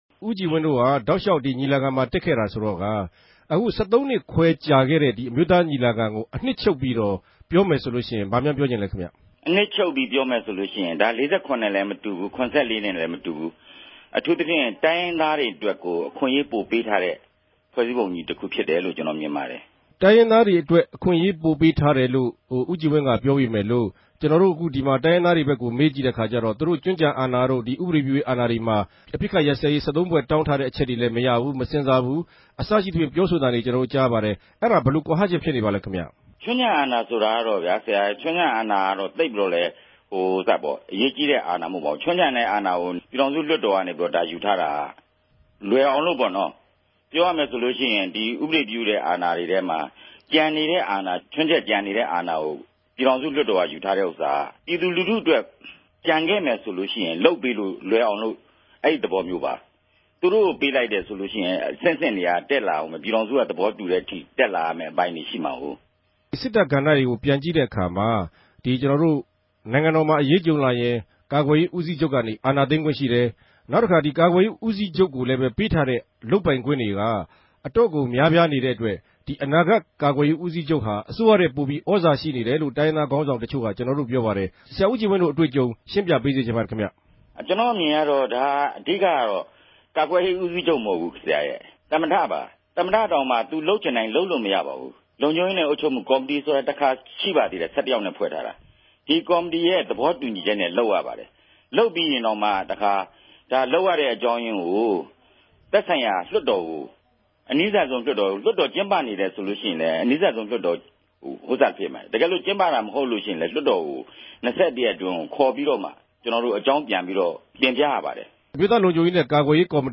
ဗန်ကောက် RFA႟ုံးခြဲကနေ ဆက်သြယ် မေးူမန်းထားပၝတယ်။